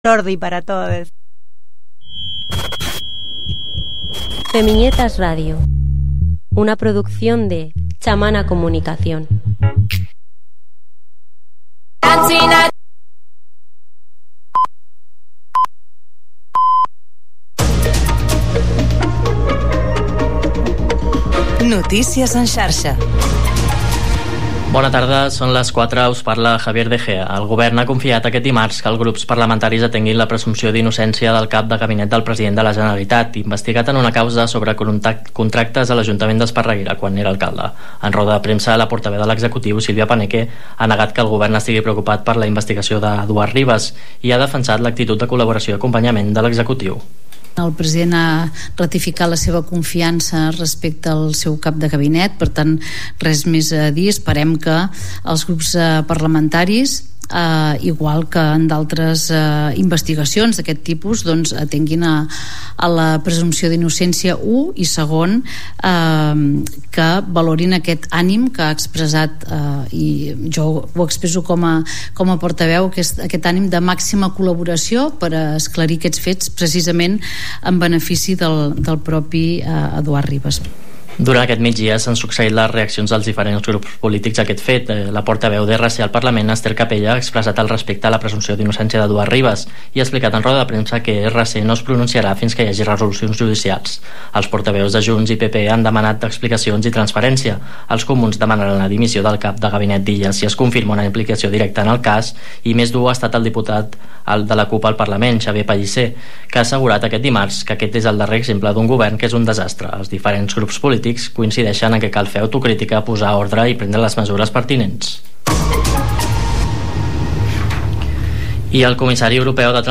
Un magazín de tarda ple d'actualitat i entreteniment.